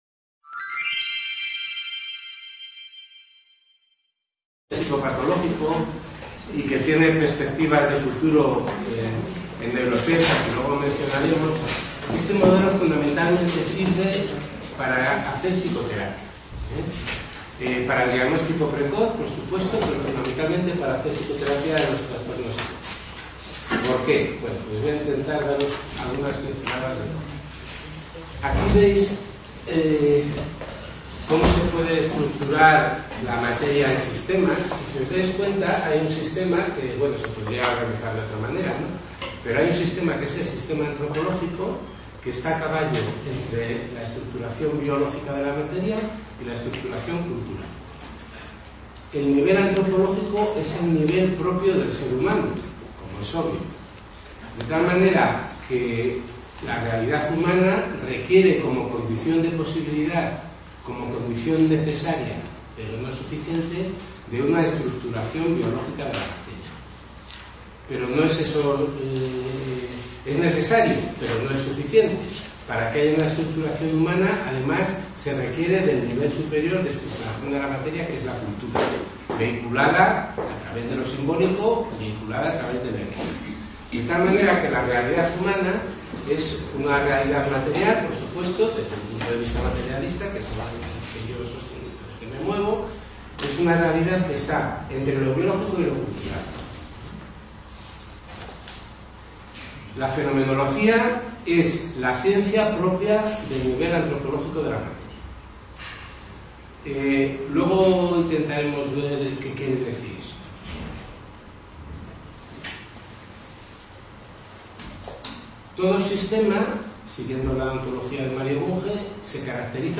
5ª sesión (Discusión Evaluación clínica) del curso de introducción a los síntomas básicos en la psicosis